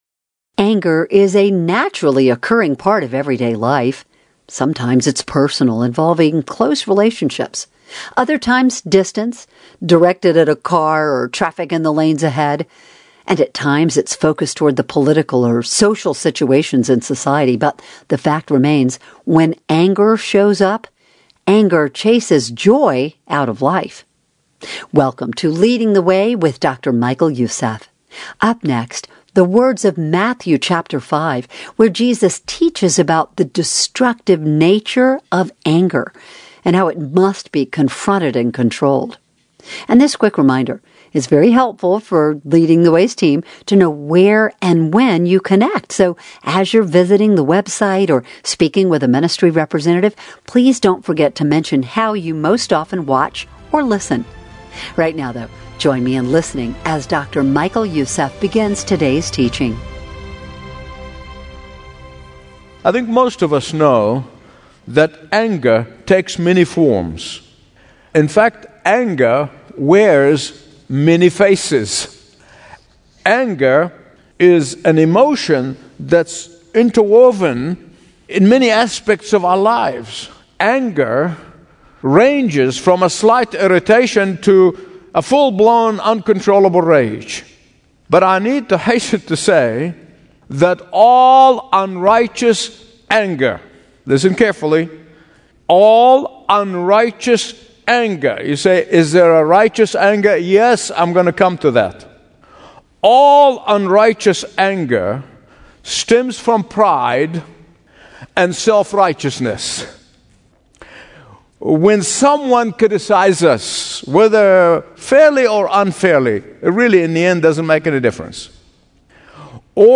Daily Bible Teachings